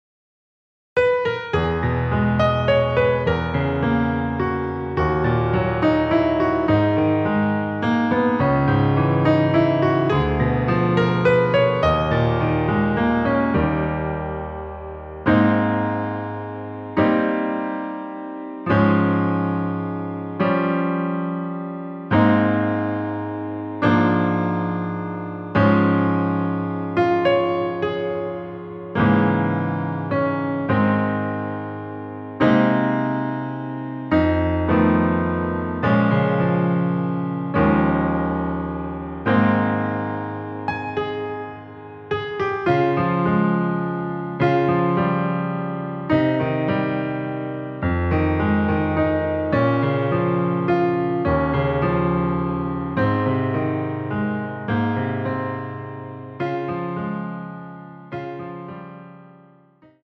Abm
◈ 곡명 옆 (-1)은 반음 내림, (+1)은 반음 올림 입니다.
앞부분30초, 뒷부분30초씩 편집해서 올려 드리고 있습니다.